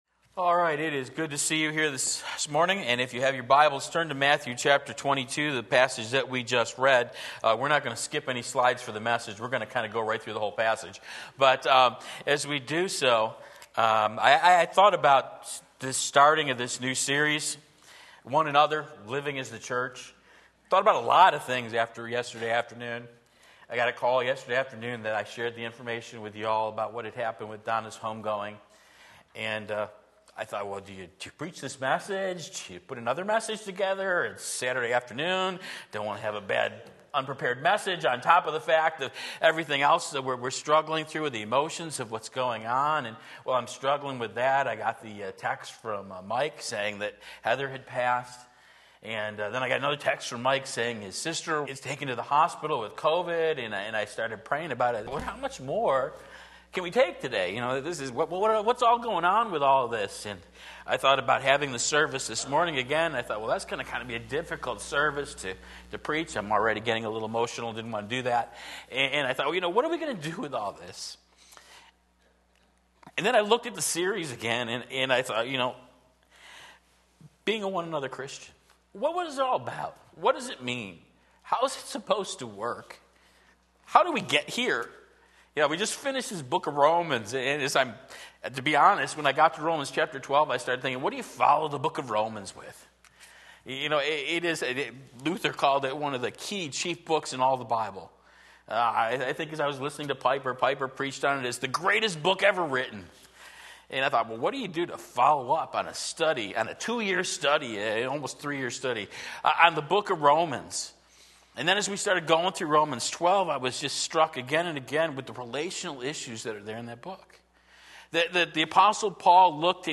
Sermon Link
Being a "One Another" Christian - Part 1 Matthew 22:34-40 Sunday Morning Service